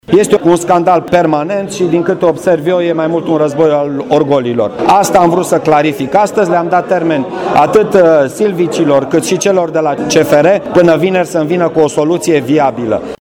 Taberele particpante la dezbatere au dat vina una pe cealaltă, spre nemulțumirea prefectului Marian Rasaliu: